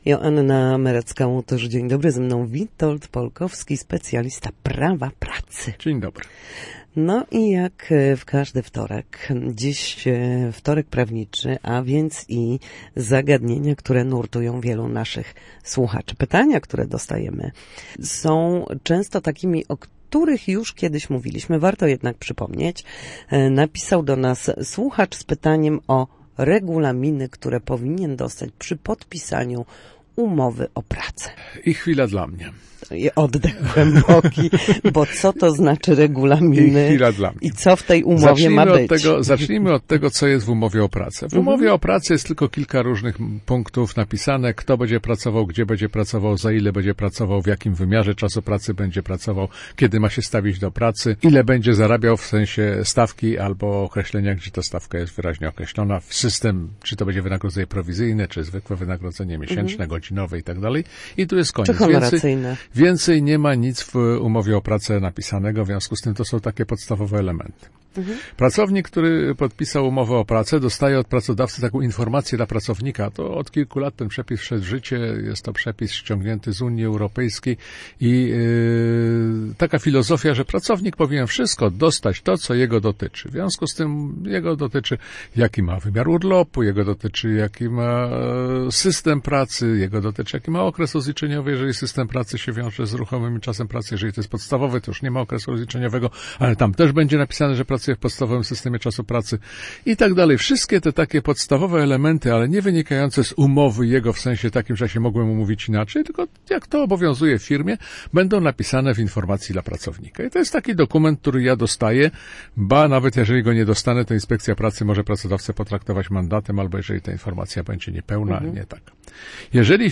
W każdy wtorek po godzinie 13:00 na antenie Studia Słupsk przybliżamy zagadnienia dotyczące prawa pracy.